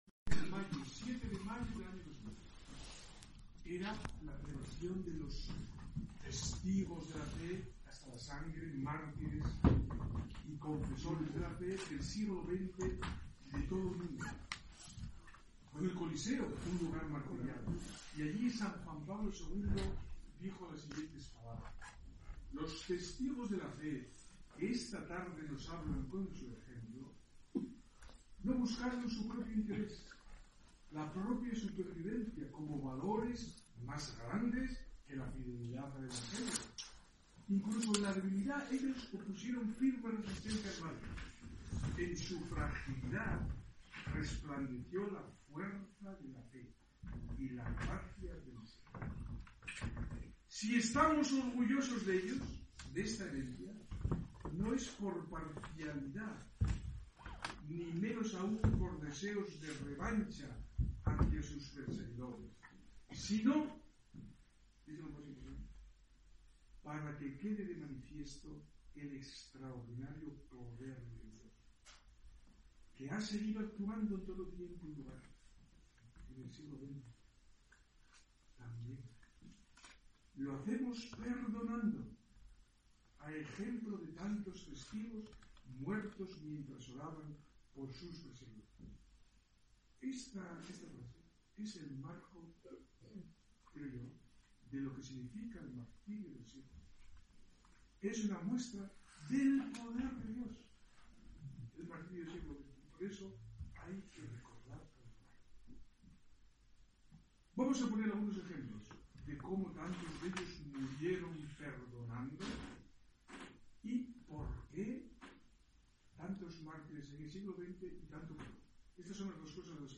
conferencia-mons-martinez-camino-6-de-noviembre